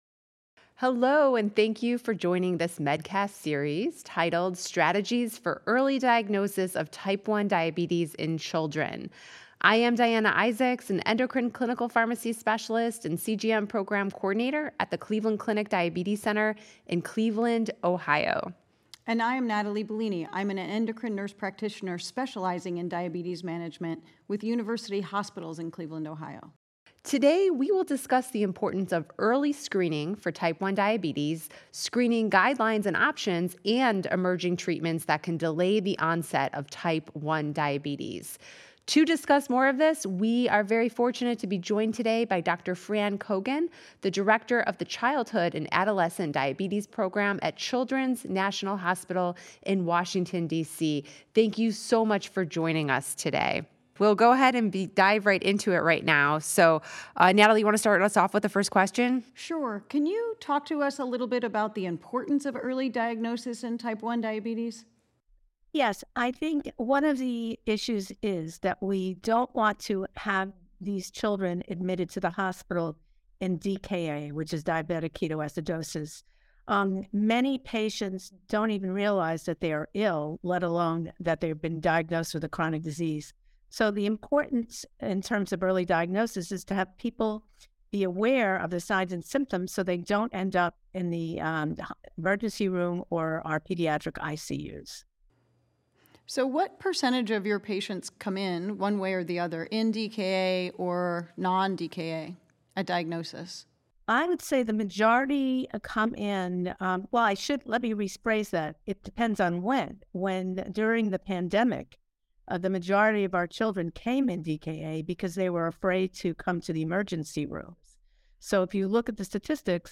The conversation covers screening protocols and options, as well as emerging treatments that can delay the onset of the disease.